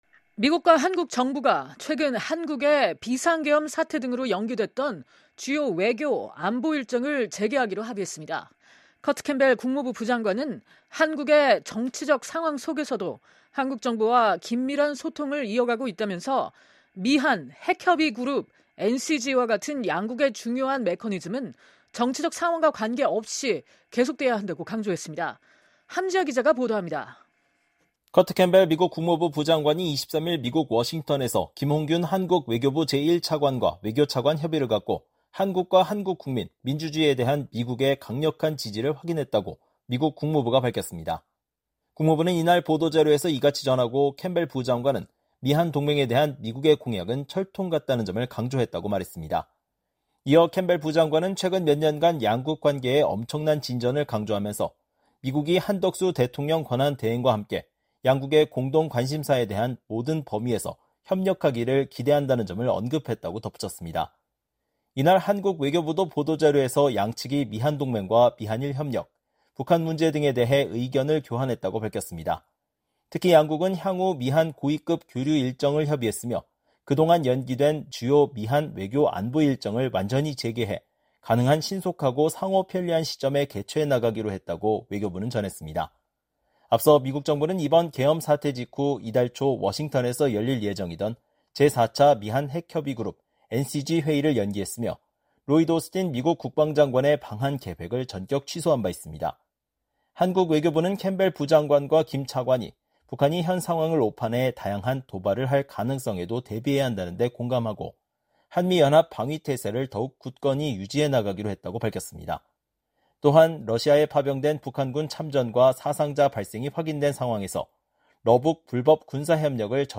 기자가 보도합니다.